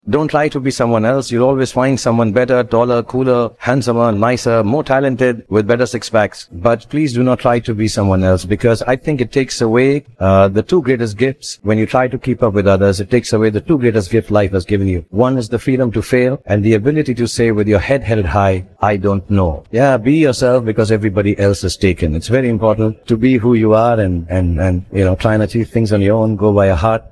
RVC Data Prep is an advanced tool for transforming audio/video content into isolated vocals.
Shahrukh Khan's Speech
Vocals